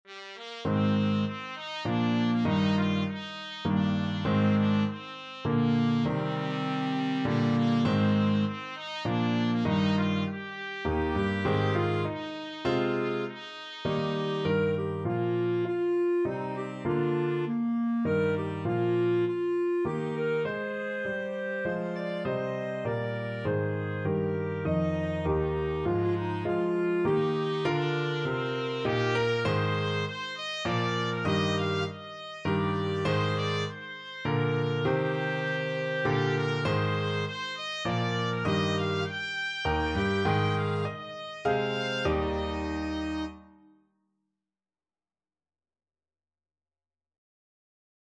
Free Sheet music for Flexible Ensemble and Piano - 2 Players and Piano
Trumpet
Clarinet
Piano
Eb major (Sounding Pitch) (View more Eb major Music for Flexible Ensemble and Piano - 2 Players and Piano )
3/4 (View more 3/4 Music)
Easy Level: Recommended for Beginners with some playing experience
Classical (View more Classical Flexible Ensemble and Piano - 2 Players and Piano Music)